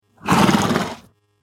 دانلود صدای اسب 2 از ساعد نیوز با لینک مستقیم و کیفیت بالا
جلوه های صوتی